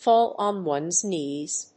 fáll [gò (dówn)] on one's knées